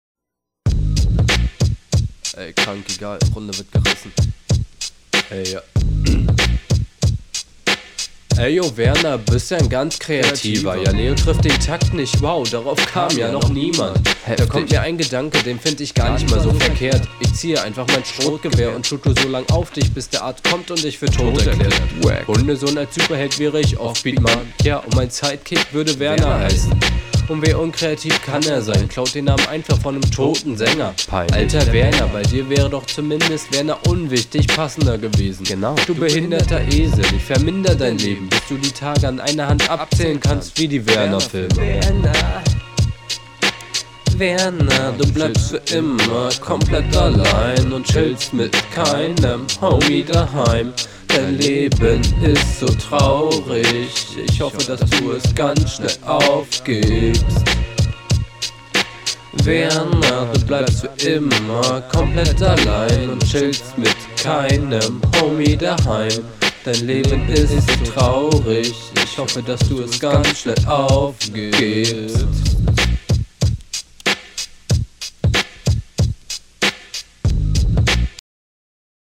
Flow arg neben dem Takt